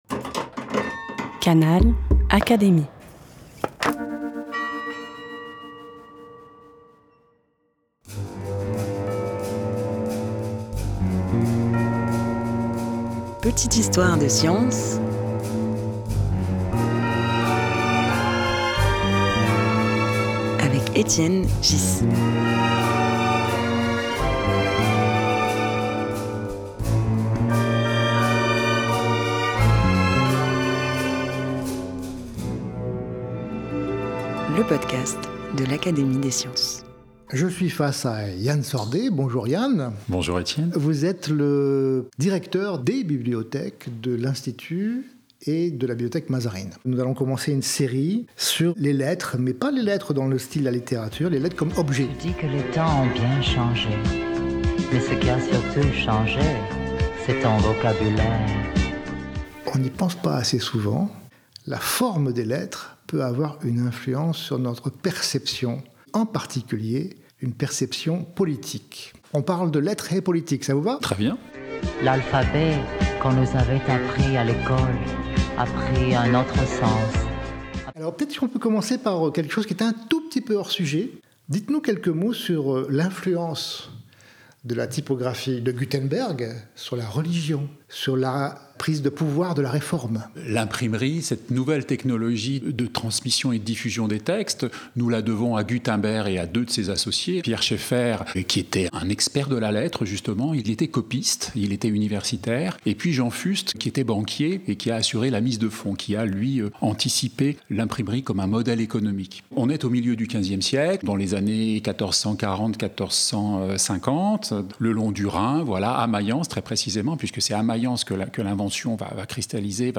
Un podcast proposé par l'Académie des sciences, animé par Étienne Ghys.